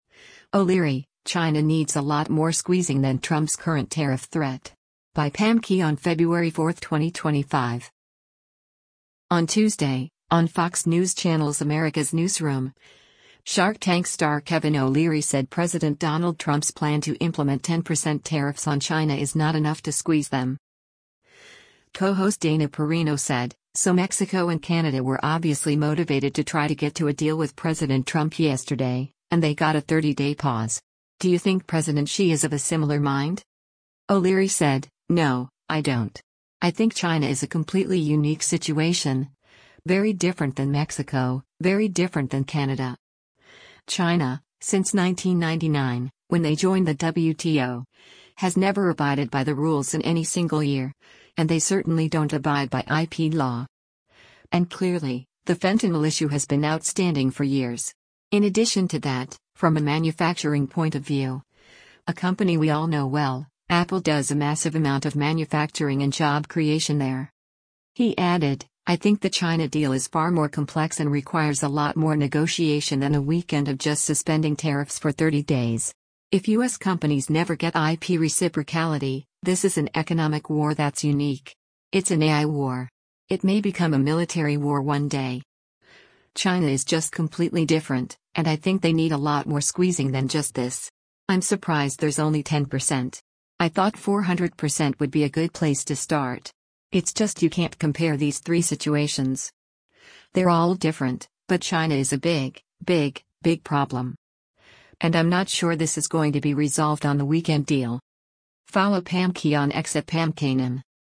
On Tuesday, on Fox News Channel’s “Americas Newsroom,” Shark Tank star Kevin O’Leary said President Donald Trump’s plan to implement 10% tariffs on China is not enough to squeeze them.